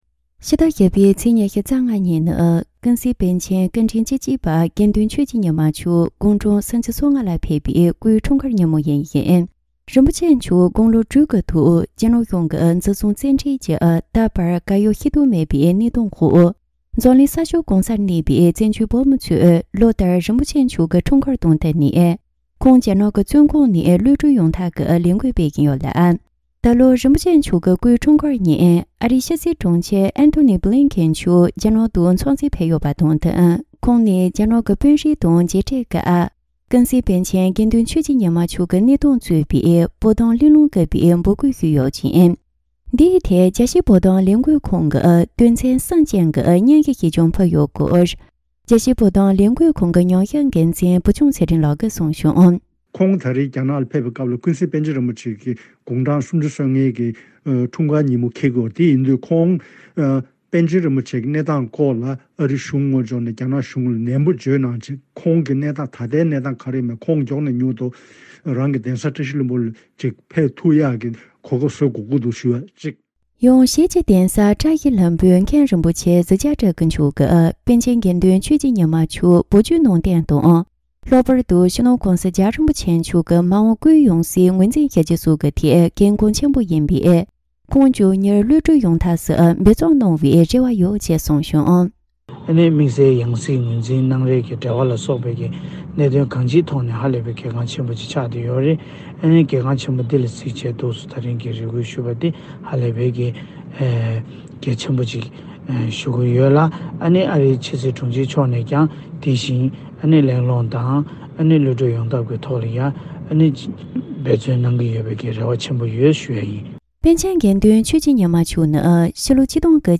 གནས་ཚུལ་ཕྱོགས་བསྡུས་ཀྱིས་སྙན་སྒྲོན་ཞུས་པར་གསན་རོགས།